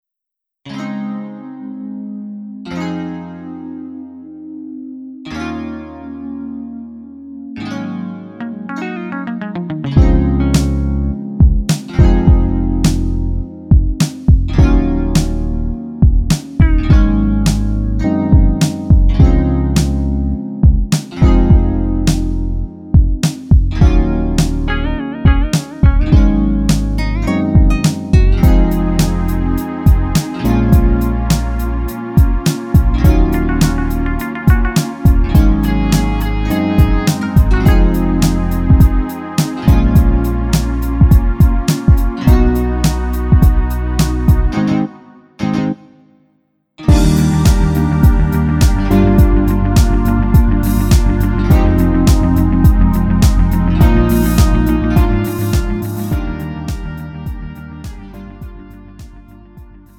음정 -1키 3:41
장르 구분 Lite MR